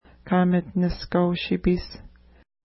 Pronunciation: ka:mətnəʃka:w-ʃi:pi:s
Pronunciation